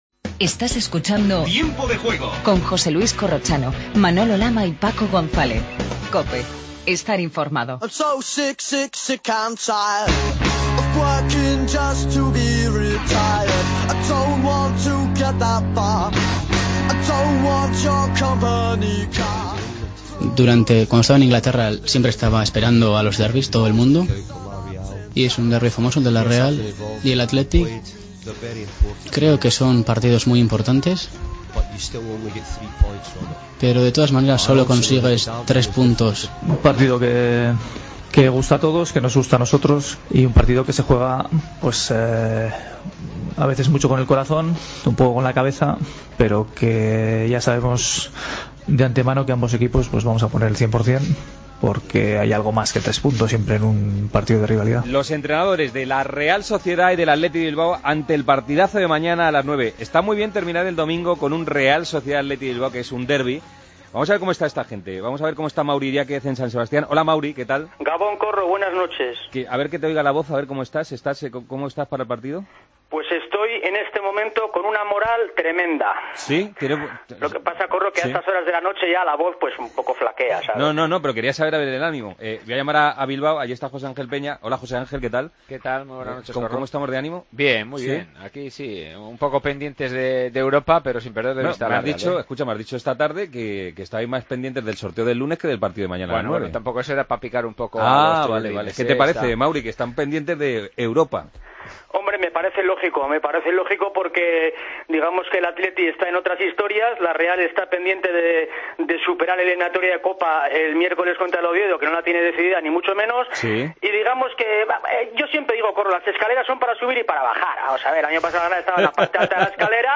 Redacción digital Madrid - Publicado el 14 dic 2014, 02:01 - Actualizado 14 mar 2023, 00:57 1 min lectura Descargar Facebook Twitter Whatsapp Telegram Enviar por email Copiar enlace Repaso del resto de la jornada con especial mención al derbi vasco en Anoeta. En el Europeo femenino de Balonmano, las Guerreras han caído frente a Noruega. Entrevista a Nerea Pena.